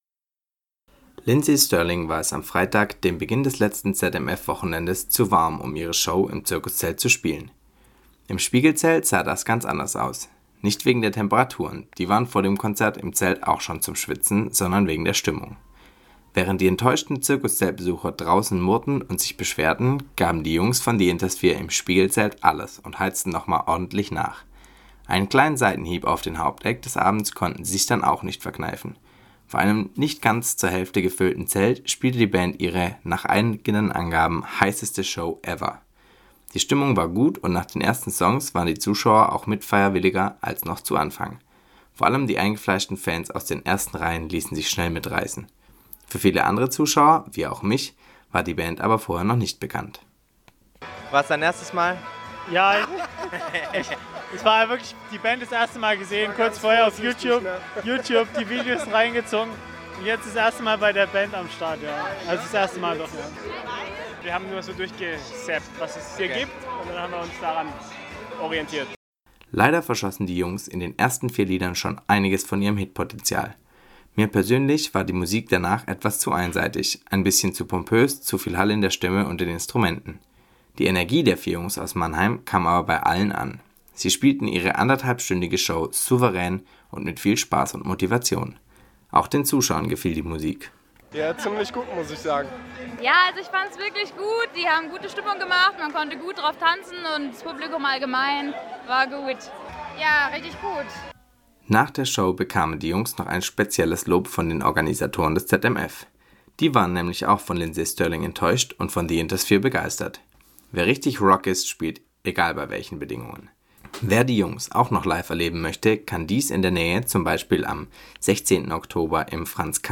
Gebauter Beitrag